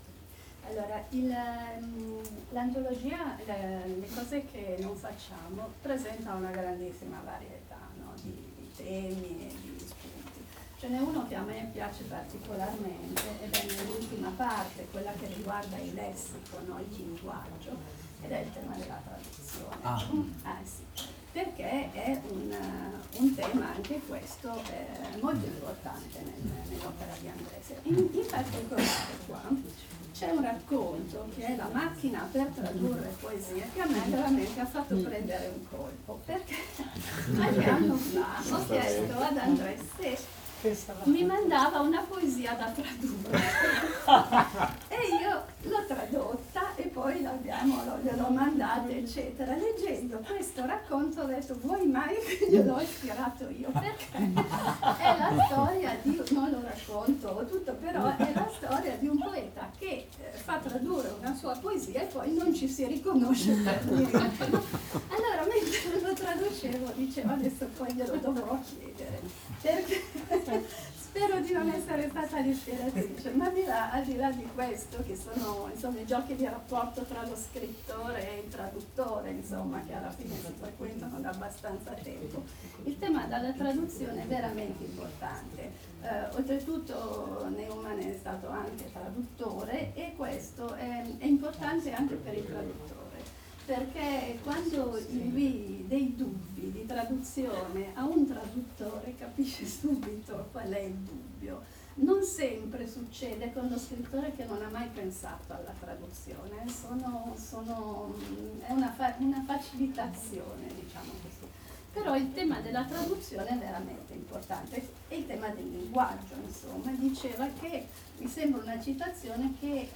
(Parma, Librería Diari di Bordo, presentación de la antología de cuentos Le cose che non facciamo (Sur, trad.